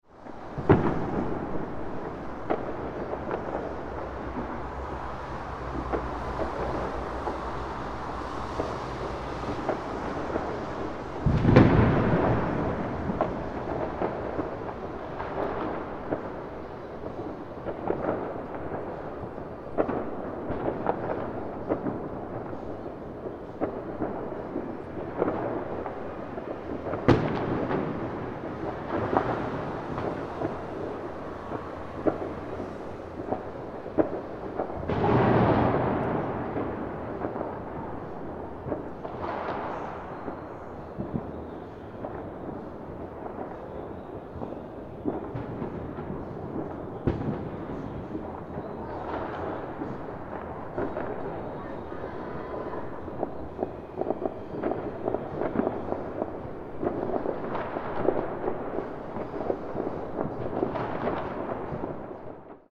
City Ambience Before Midnight Fireworks – New Year’s Eve Street Sound Effect
Authentic city streets come alive with subtle traffic and distant chatter in this city ambience before midnight fireworks – new year’s eve street sound effect, perfect for films, videos, games, and projects needing a realistic pre-fireworks New Year’s Eve mood.
Genres: Sound Effects
City-ambience-before-midnight-fireworks-new-years-eve-street-sound-effect.mp3